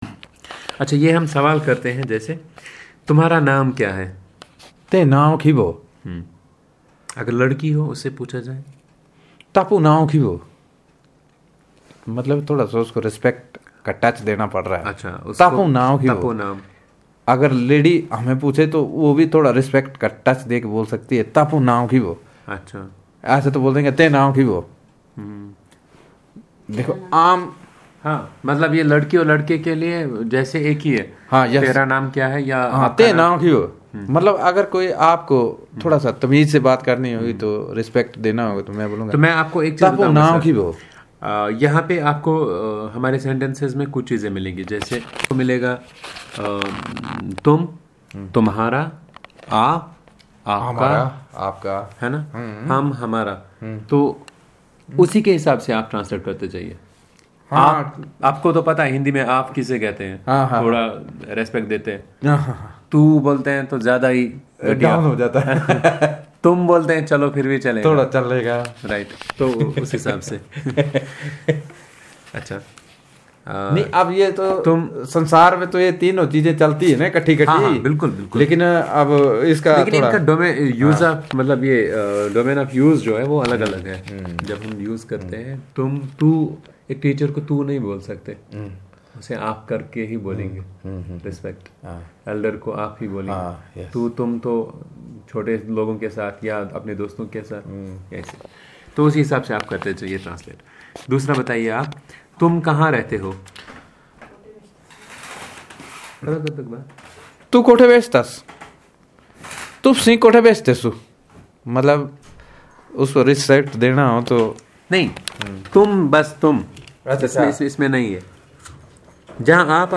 Elicitation of sentences about interrogatives